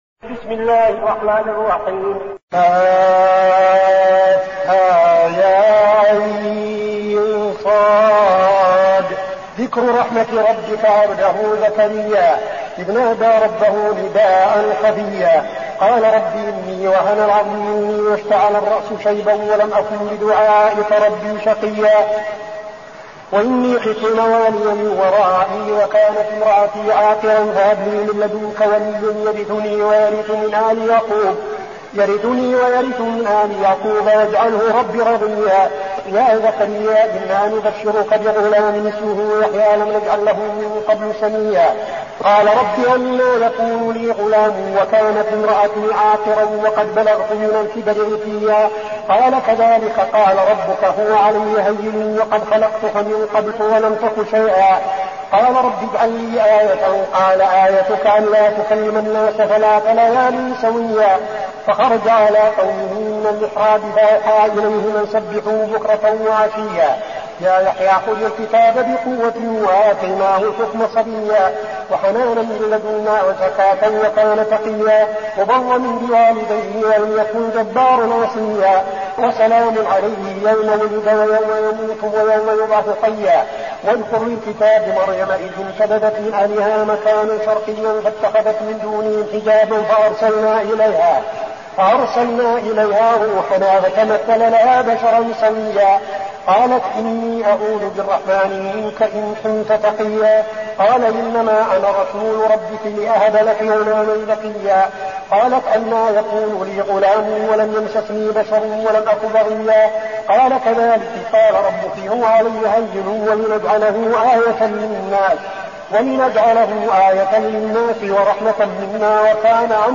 المكان: المسجد النبوي الشيخ: فضيلة الشيخ عبدالعزيز بن صالح فضيلة الشيخ عبدالعزيز بن صالح مريم